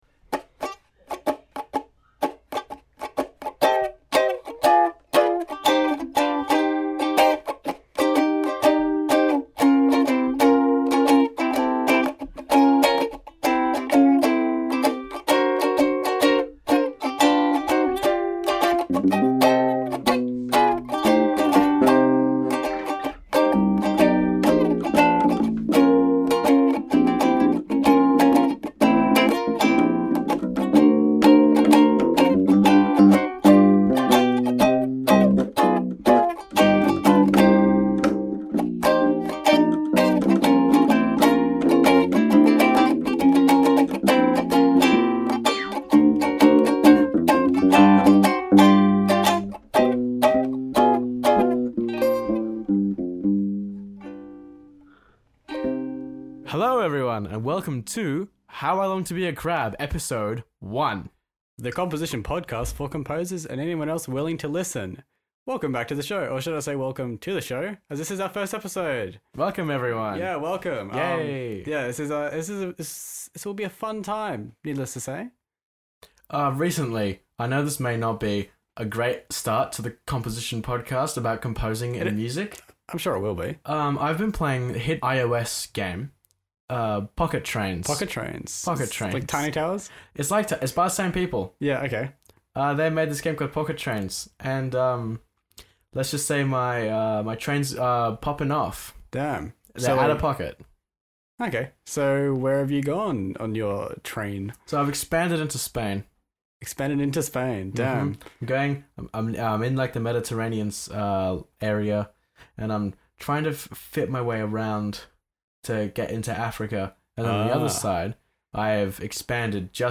A podcast for those who love listening to two guys talking about music and other unrelated topics.